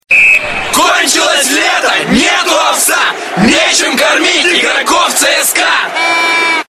Футбольные кричалки